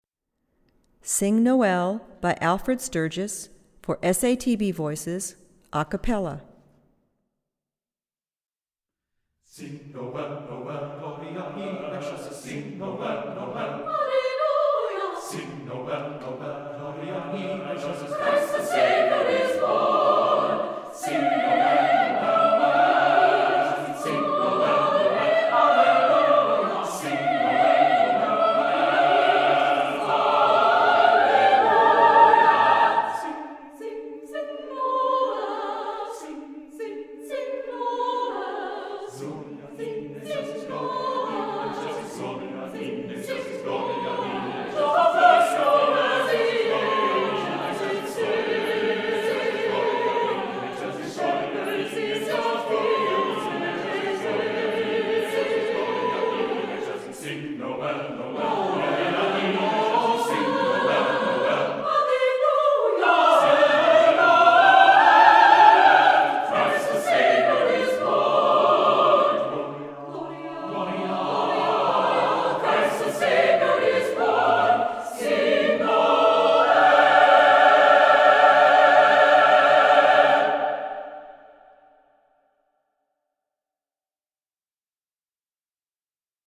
SATB a cappella Level